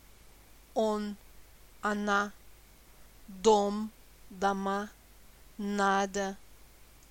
3- о et а se prononcent de la même façon en dehors de l’accent: un a bref réduit, presque e (eu)